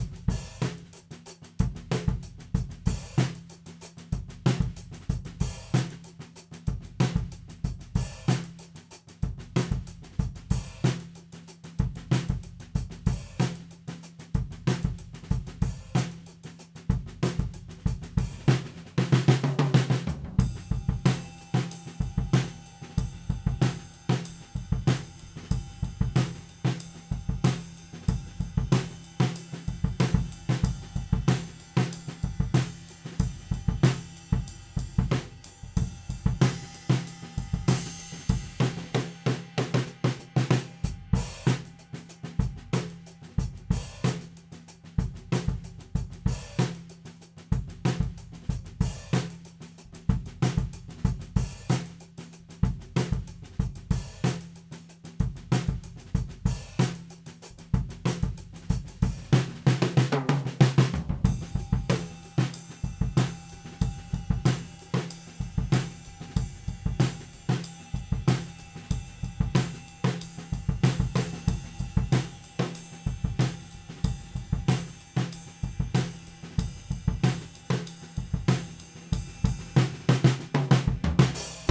We tracked first through the 4 preamps on the older generation Apollo, then immediately switched the mic cables into the first 4 preamp channels on the Apollo 8P.
First, the old generation Apollo Quad: